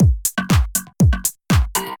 FUNKY_HOUSE.mp3